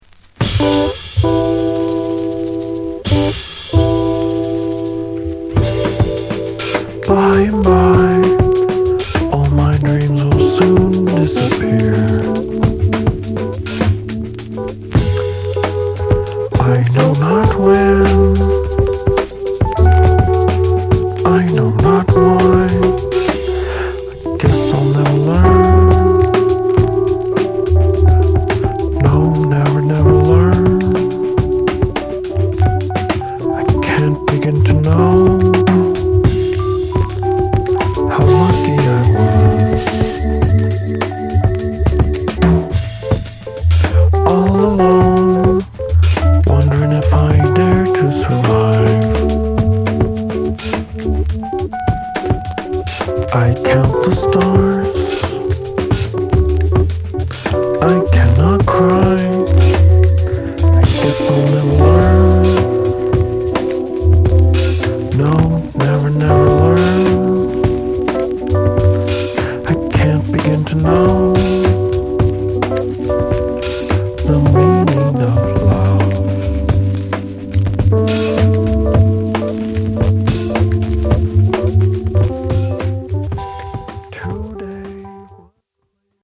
vibes
percussion
bass
drums